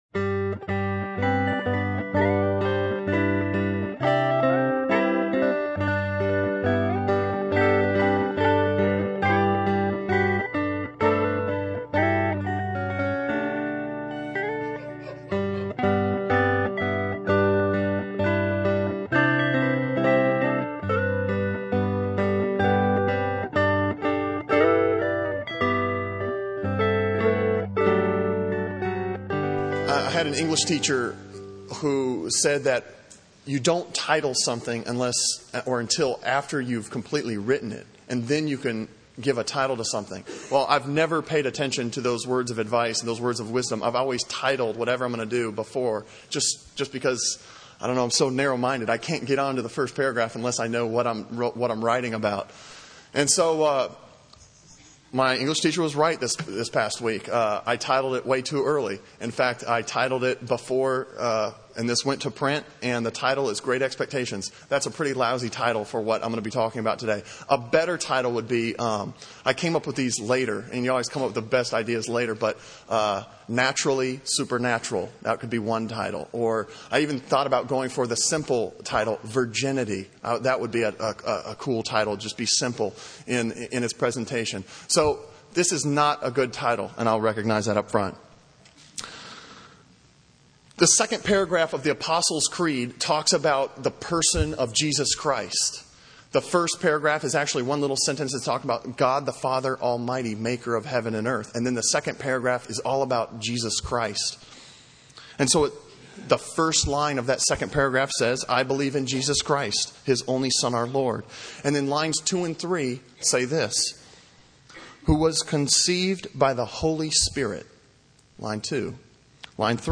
Sermon on Luke 1:26-38 from December 17, 2006